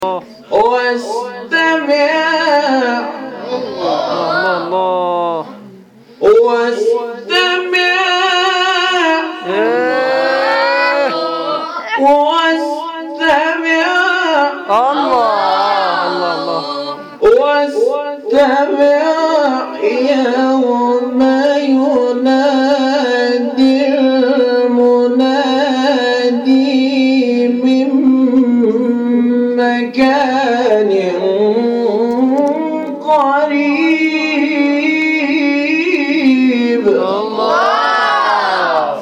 گروه شبکه اجتماعی: فرازهای صوتی از تلاوت قاریان بنام و ممتاز کشور را می‌شنوید.